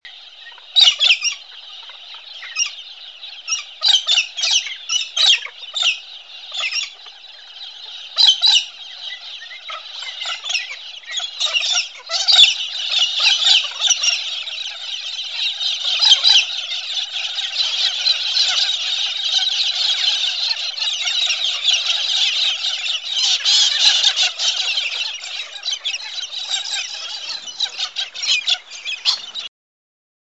Australian Nature Sounds
Galahs Galahs
Galahs.mp3